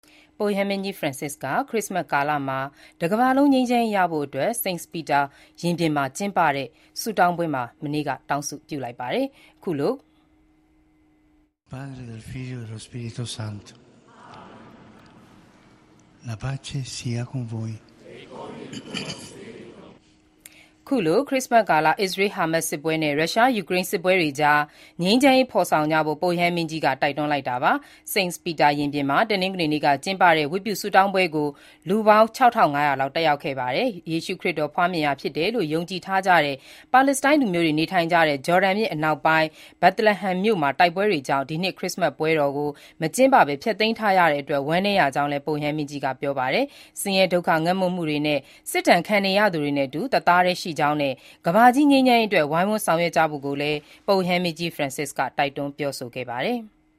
ခရစ္စမတ်ကာလ တကမ္ဘာလုံး ငြိမ်းချမ်းရေးရဖို့အတွက် စိန့်ပီတာ ရင်ပြင်မှာ တနင်္ဂနွေနေ့က ကျင်းပတဲ့ ဝတ်ပြုဆုတောင်းပွဲမှာ ပုပ်ရဟန်းမင်းကြီး ဖရန်စစ်က တောင်းဆုပြုလိုက်ပါတယ်။ ခရစ္စမတ်ကာလ အစ္စရေး-ဟာမတ်စ် စစ်ပွဲနဲ့ ရုရှား- ယူကရိန်းစစ်ပွဲတွေကြား ငြိမ်းချမ်းရေးဖော်ဆောင်ကြဖို့ ပုပ်ရဟန်းမင်းကြီးက တိုက်တွန်းတာပါ